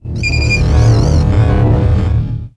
星际争霸音效-protoss-shuttle-pshpss04.wav